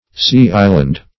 Sea-island \Sea"-is`land\